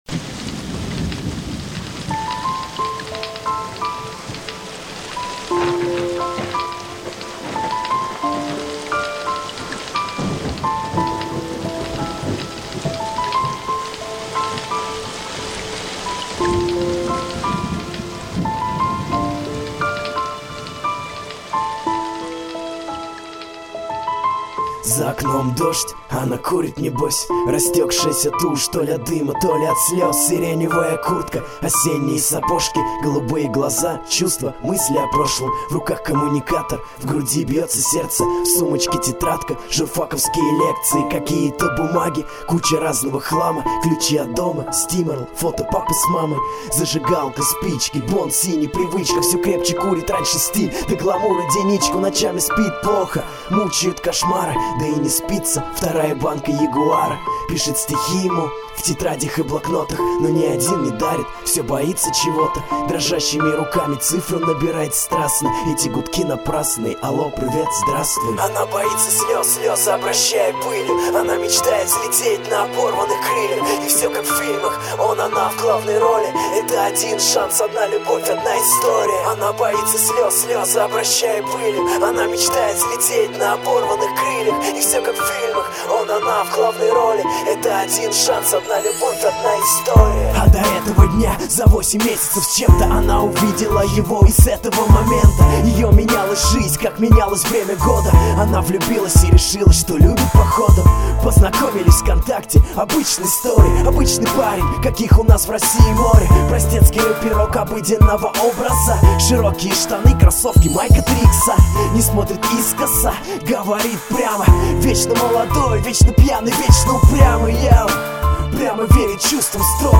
ya_tebya_nenavizhu_gstnyy_rep_pro_lyubov_1000.mp3